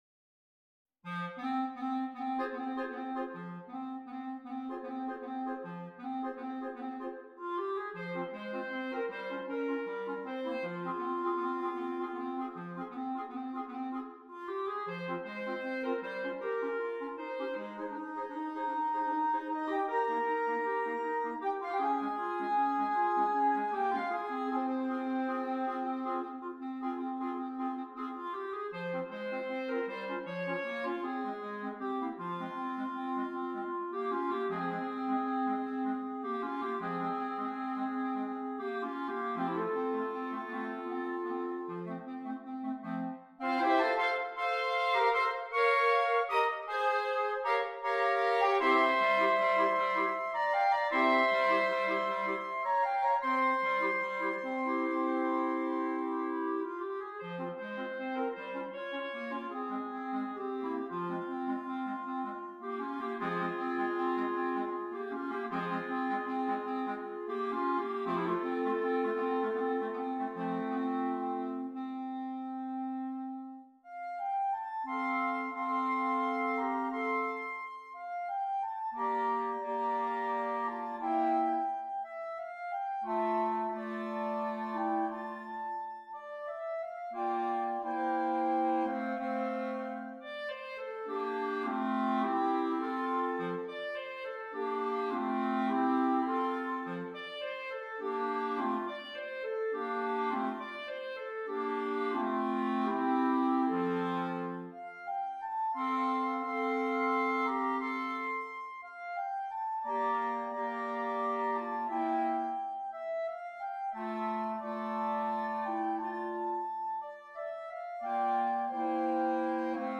6 Clarinets